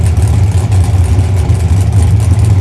v8_idle_nascar1.wav